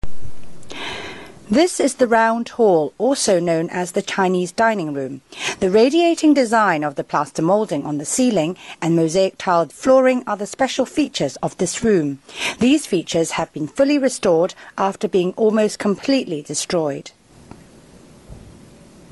Vocal Description